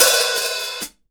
Closed Hats
TC3Hat15.wav